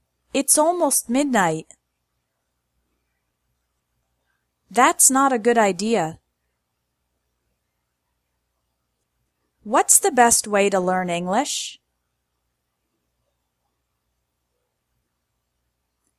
‘s pronounced S: